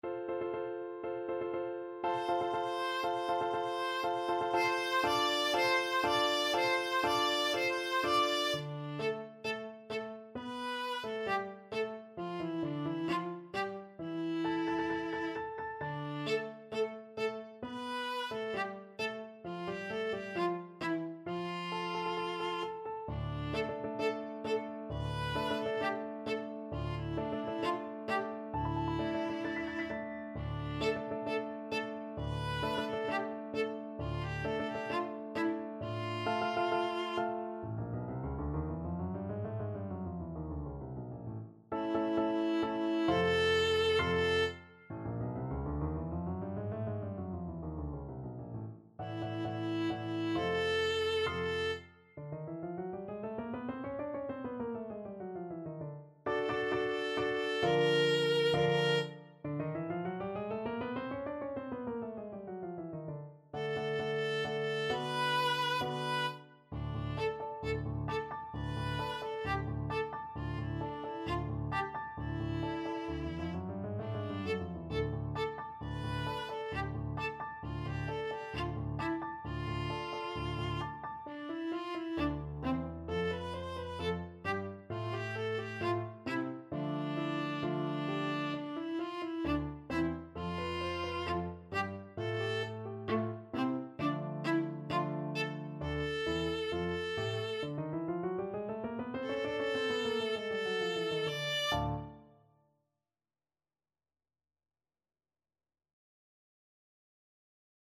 Classical
Viola version